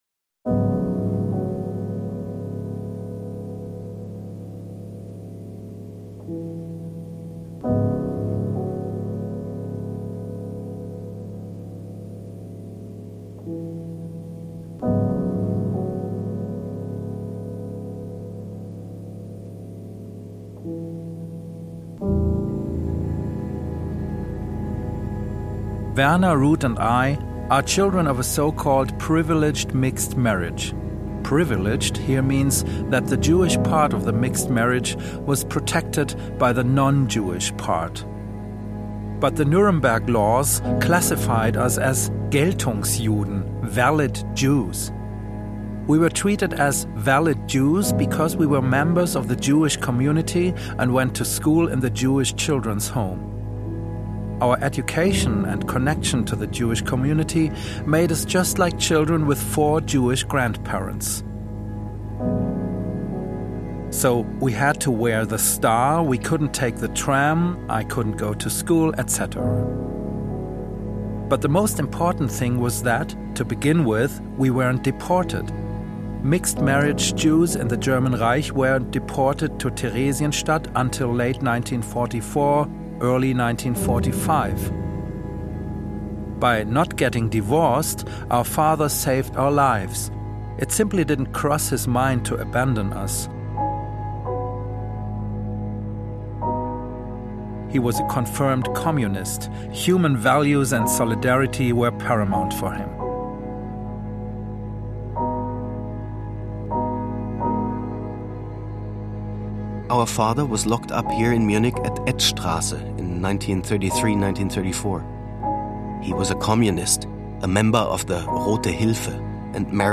These voice-collages were broadcast as a radio series on Bayern 2 in September and October 2010, and can also be downloaded as podcasts.
Memory_Loops_-_1_-_English_Radio_Play